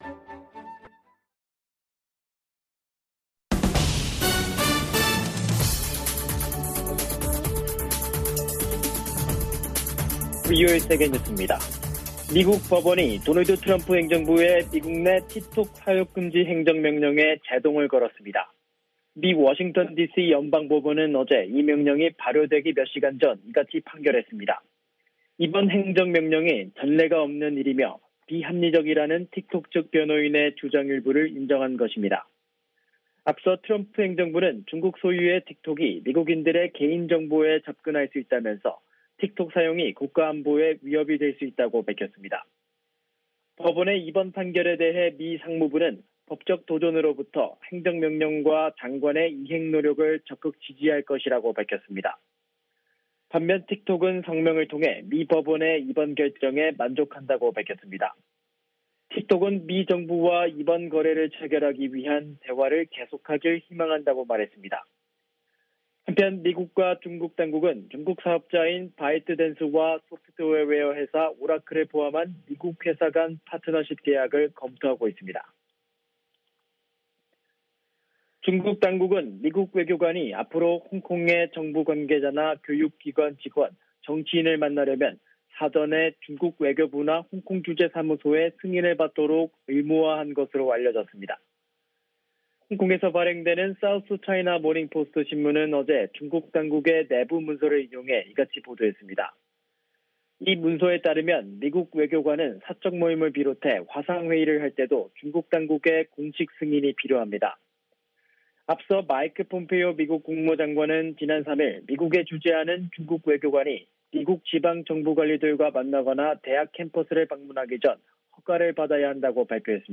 VOA 한국어 간판 뉴스 프로그램 '뉴스 투데이', 2부 방송입니다.